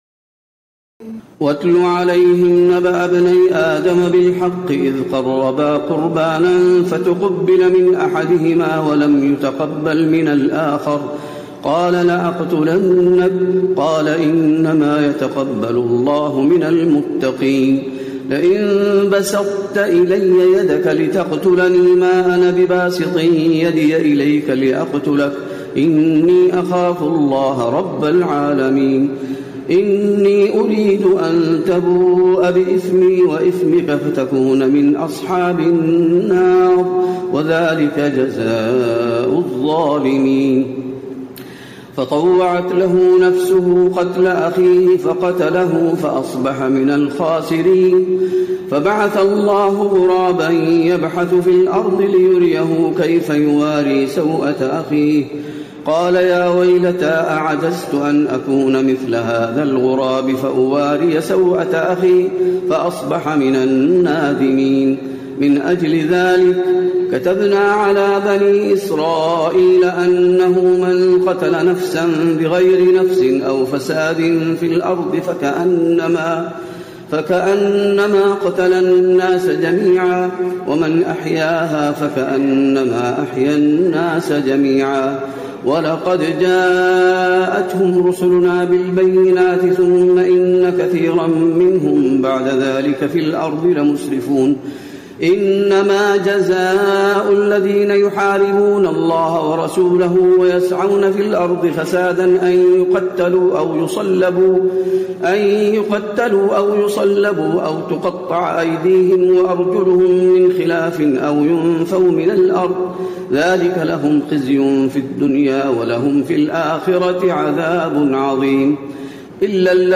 تراويح الليلة السادسة رمضان 1436هـ من سورة المائدة (27-56) Taraweeh 6 st night Ramadan 1436H from Surah AlMa'idah > تراويح الحرم النبوي عام 1436 🕌 > التراويح - تلاوات الحرمين